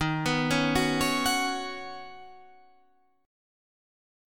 Ebm7#5 chord